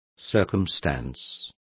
Complete with pronunciation of the translation of circumstance.